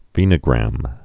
(vēnə-grăm)